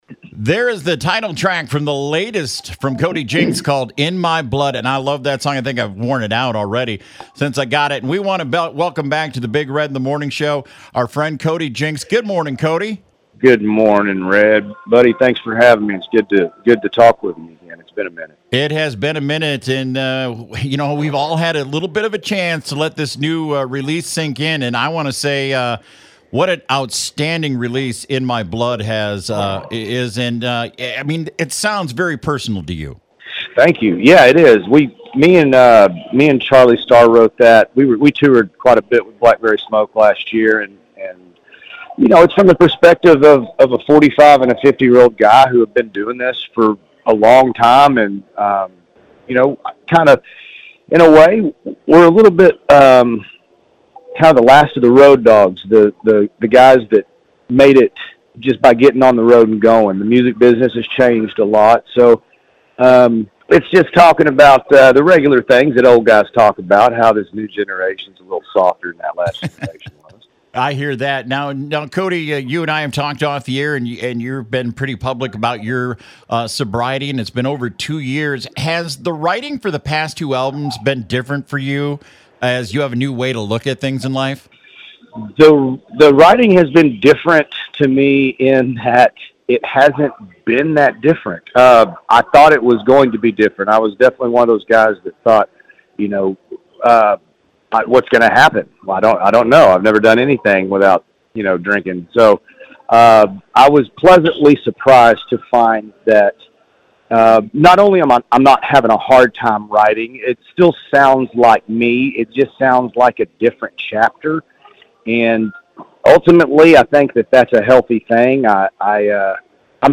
Cody Jinks Interview